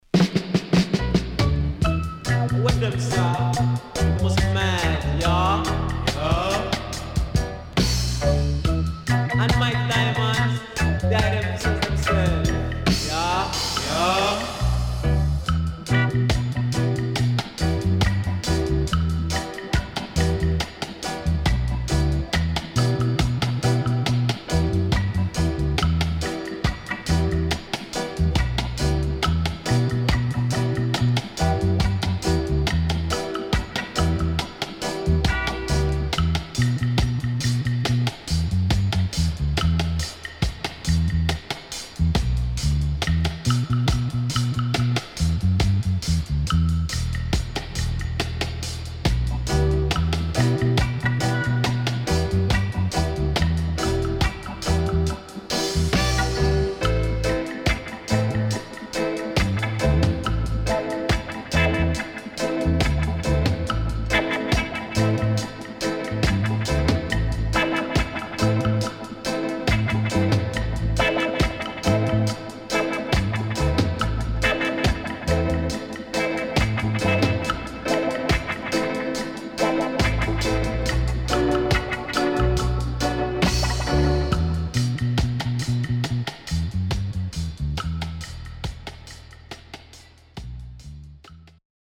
SIDE A:少しプチノイズ入ります。